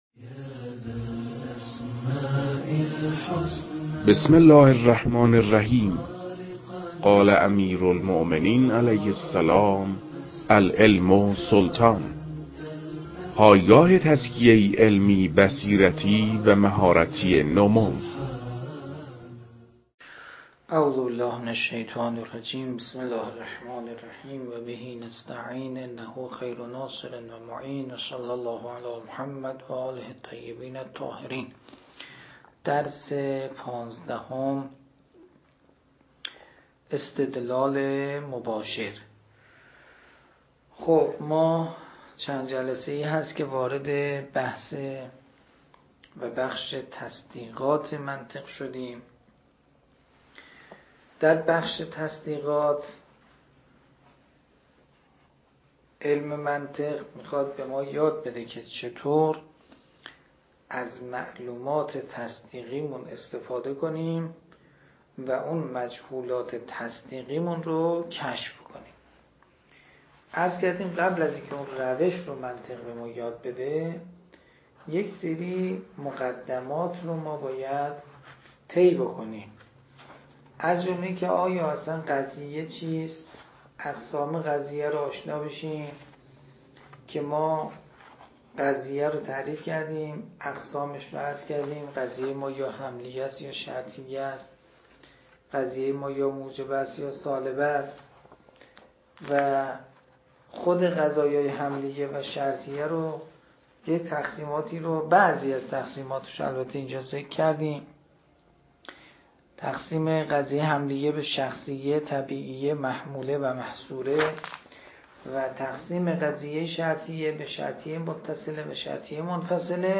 در این بخش، کتاب «درآمدی بر منطق» که اولین کتاب در مرحلۀ آشنایی با علم منطق است، به صورت ترتیب مباحث کتاب، تدریس می‌شود.
در تدریس این کتاب- با توجه به سطح آشنایی کتاب- سعی شده است، مطالب به صورت روان و در حد آشنایی ارائه شود.